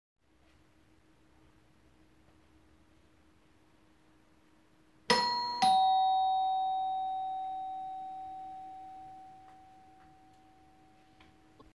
doorbell3
bell chime door doorbell ring ringing rings sound effect free sound royalty free Sound Effects